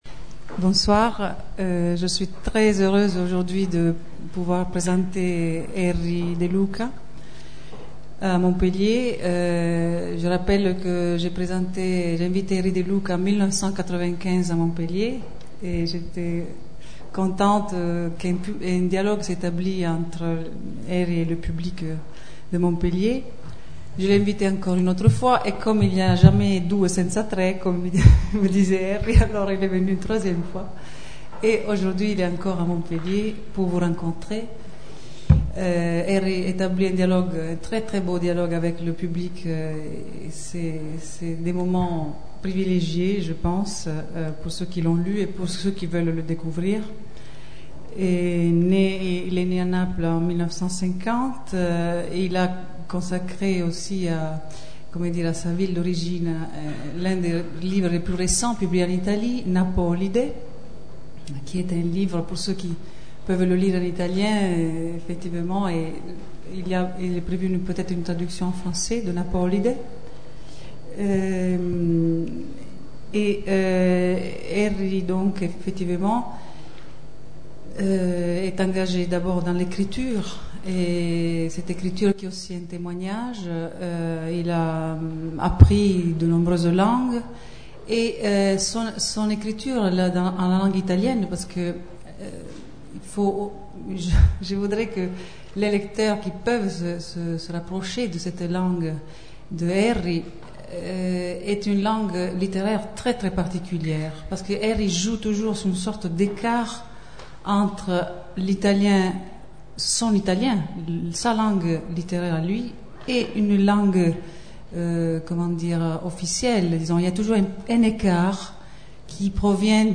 Rencontre avec Erri de Luca - Dans le cadre de la Comédie du Livre des 1, 2 et 3 juin 2007